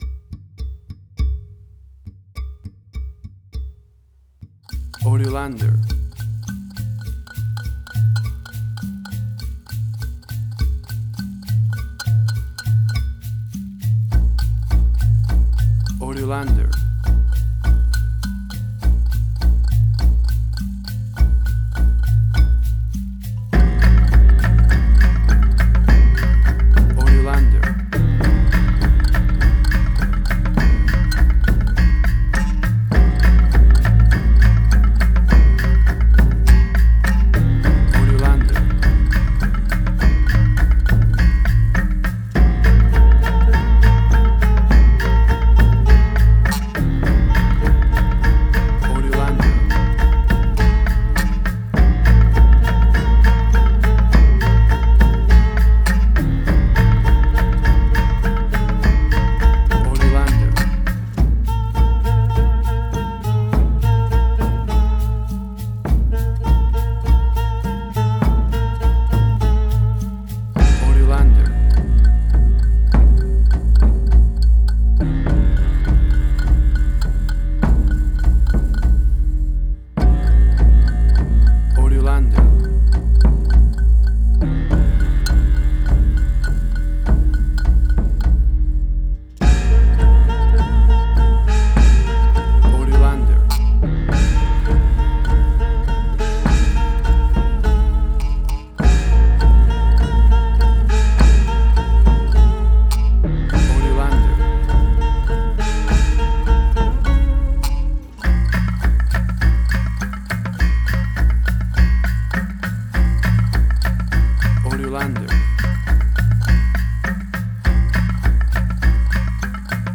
Asian Ambient.
Tempo (BPM): 102